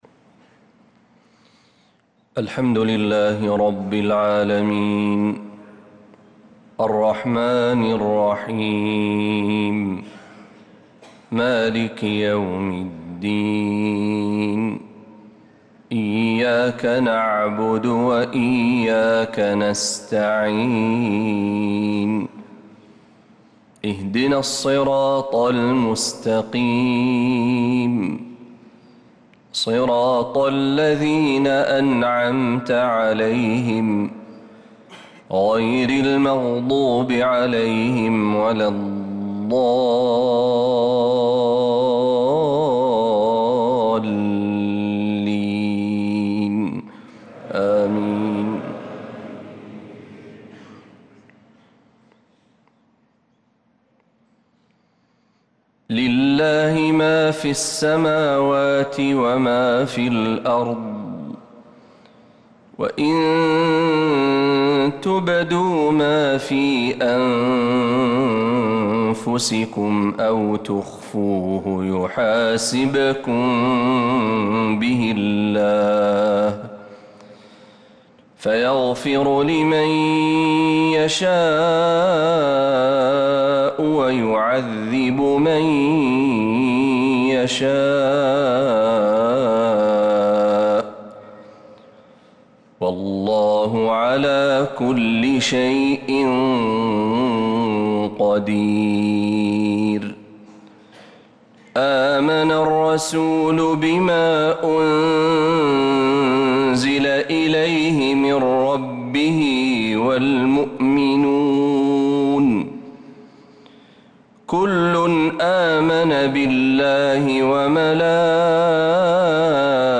صلاة المغرب للقارئ محمد برهجي 8 جمادي الأول 1446 هـ
تِلَاوَات الْحَرَمَيْن .